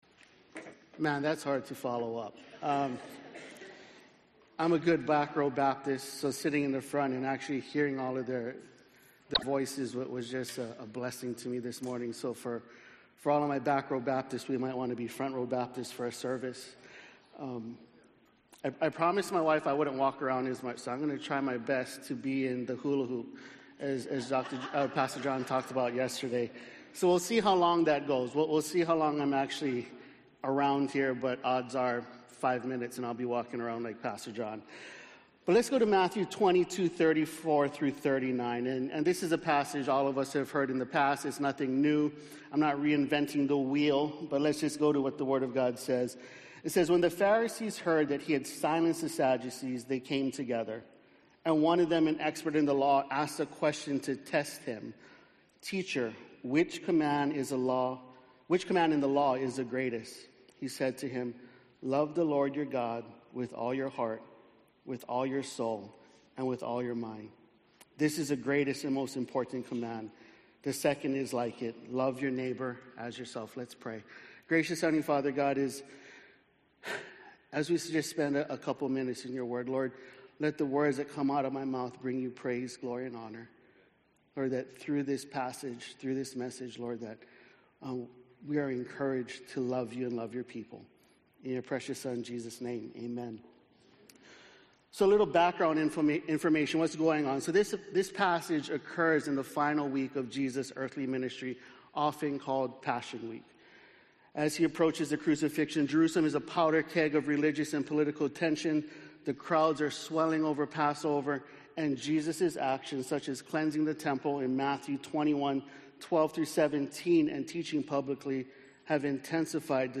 Missing Peace? #3 - Love God. Love Others - Sermons - Hallmark Church